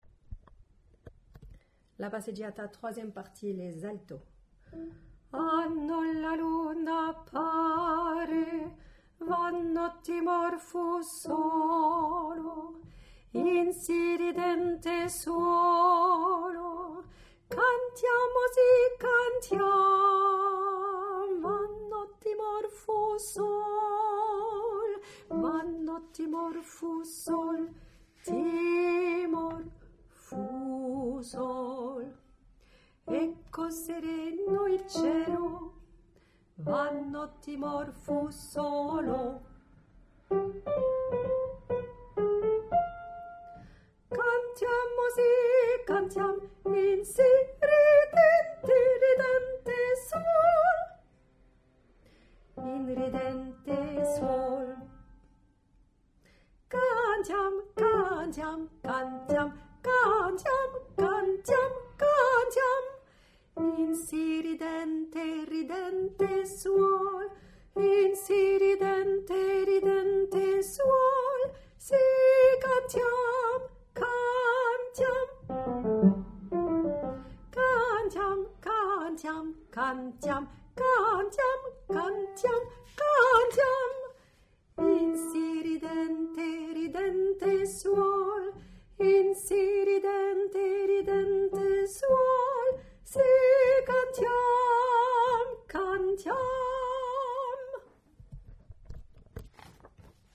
passeggiata3_Alto.mp3